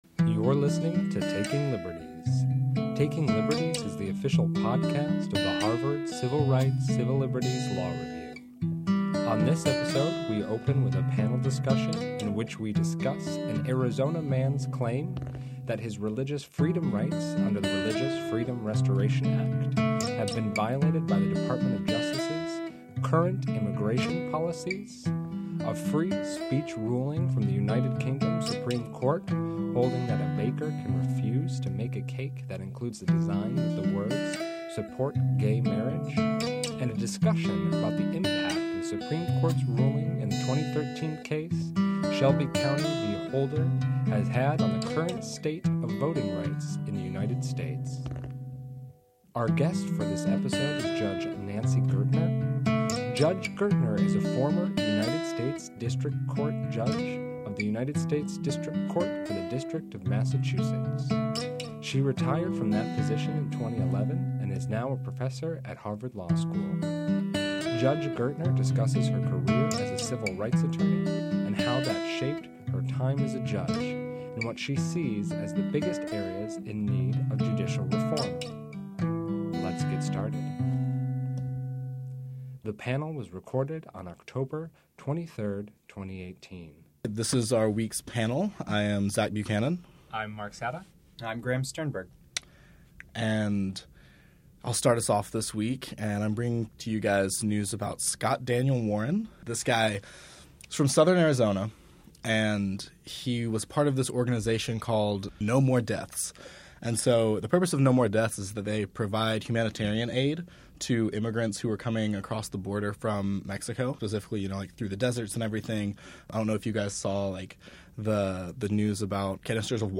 The panel discusses a RFRA claim against immigration policy, a cake-baking case from the UK Supreme Court, and the consequences of Shelby County v. Holder. Our guest is Judge Nancy Gertner who discusses her career as a civil rights attorney and how that shaped her time as a judge and what she sees as the biggest areas in need of judicial reform.